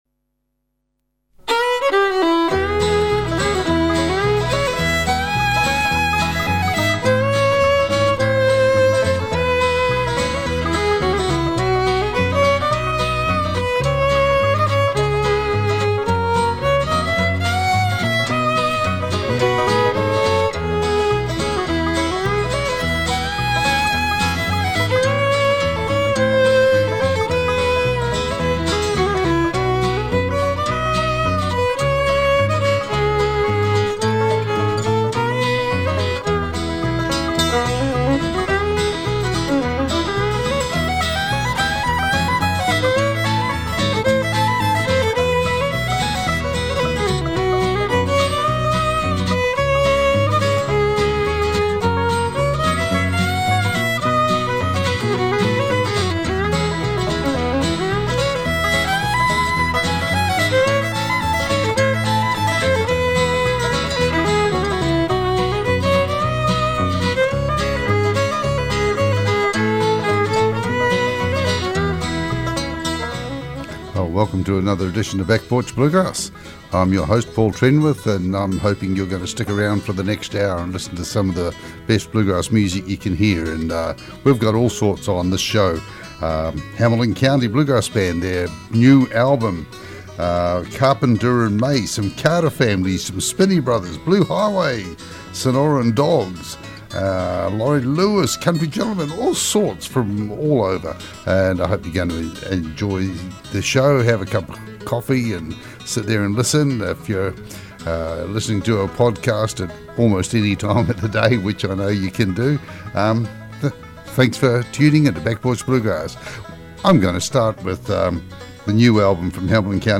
Back Porch Bluegrass Show - 07 February 2017